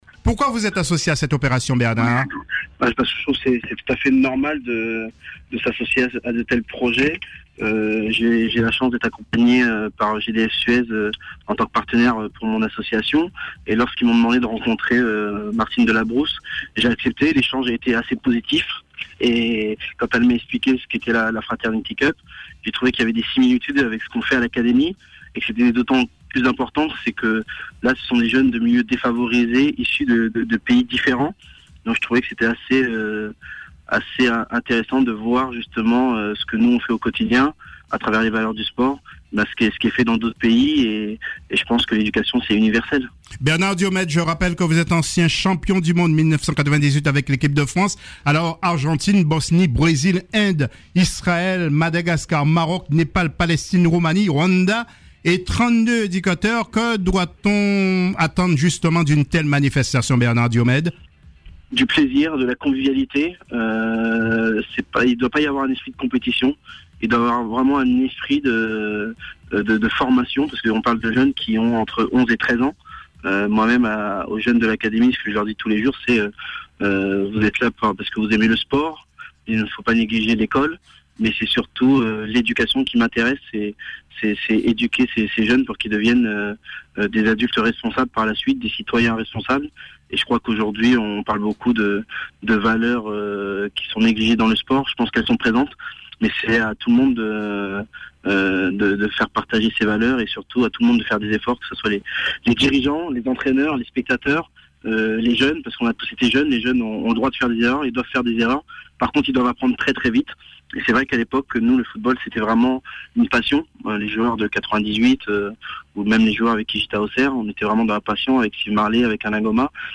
Au micro de Tropiques FM, il revient sur les raisons de son soutien à l’événement.